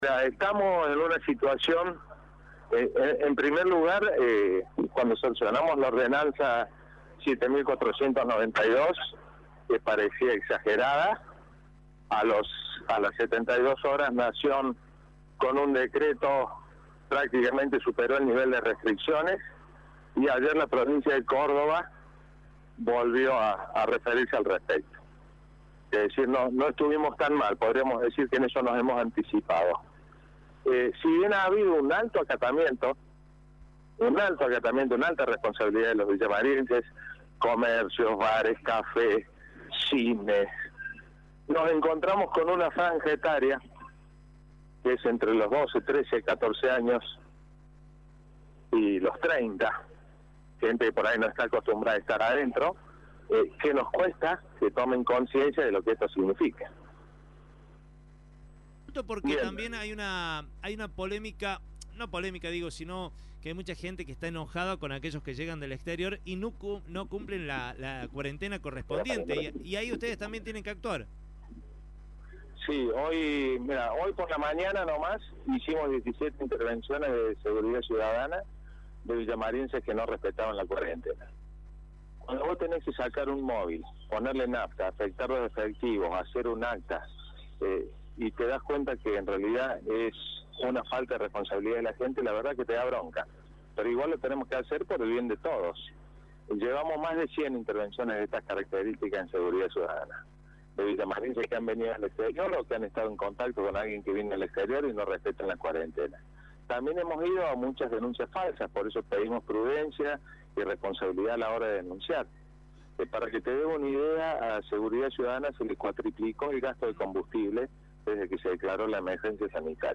El secretario de Gobierno, el abogado Eduardo Rodríguez, habló con nuestro medio sobre la falta de conciencia de parte de la ciudadanía.